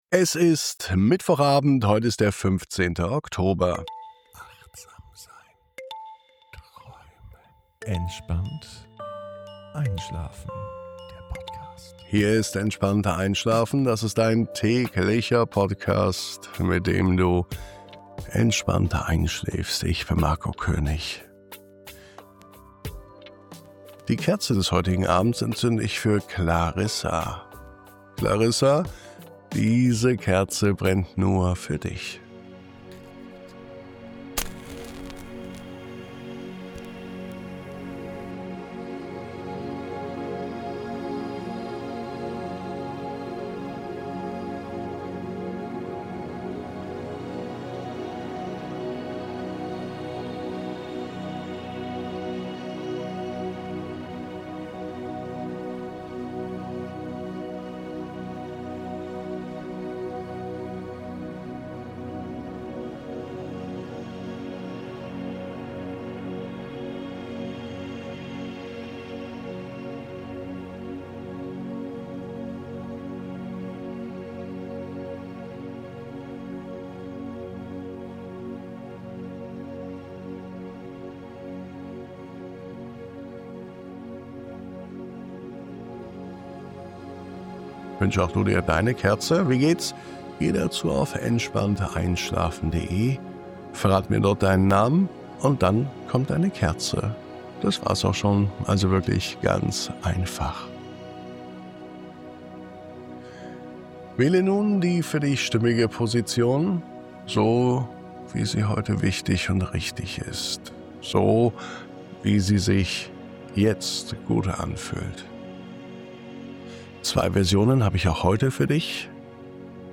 Diese Traumreise schenkt dir einen Raum jenseits von Gedanken, Listen und innerem Lärm. Du wirst eingeladen, einfach zu sein – getragen von sanfter Sprache, beruhigendem Klang und einer Stille, die nicht leer ist, sondern warm. Ein Ort zum Ankommen, zum Durchatmen, zum Einschlafen.